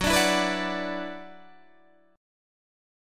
F#7sus4#5 chord